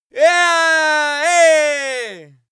Royalty free sounds: Screams